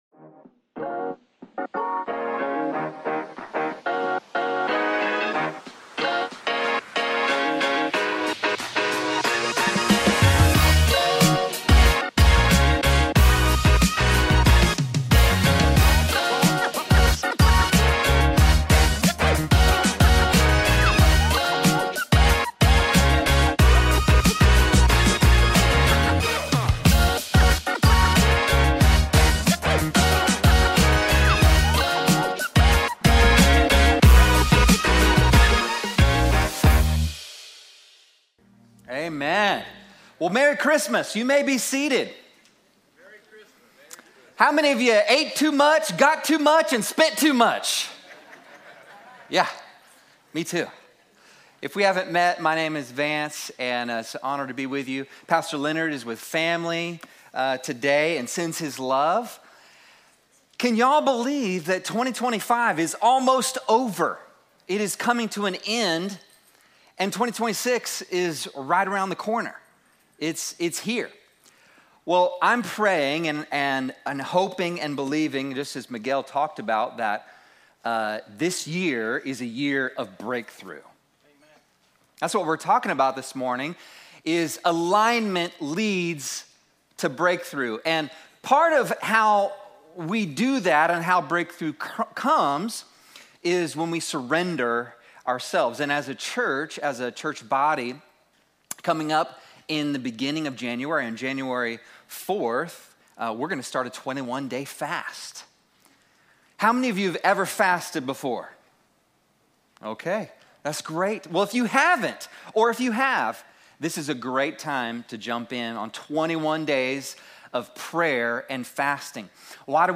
2025_12_28-Alignment-Leads-to-Breakthrough-Heartcry-Chapel-Sunday-Sermon.mp3